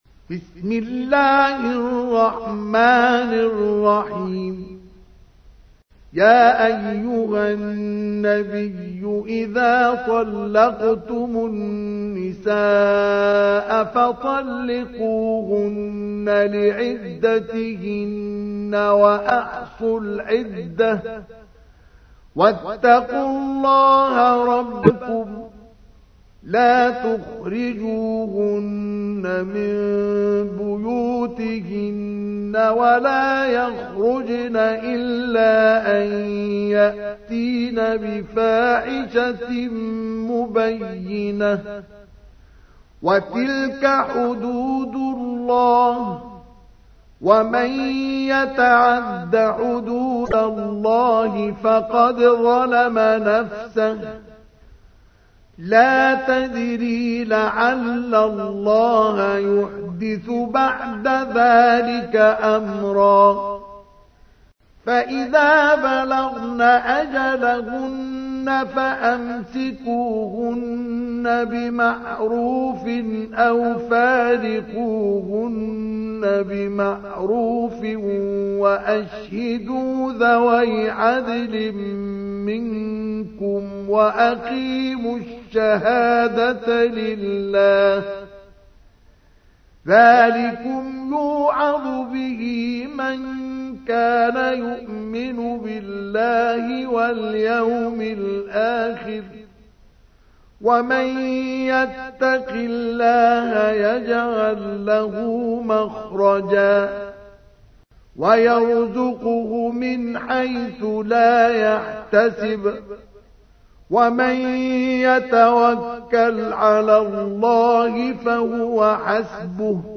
تحميل : 65. سورة الطلاق / القارئ مصطفى اسماعيل / القرآن الكريم / موقع يا حسين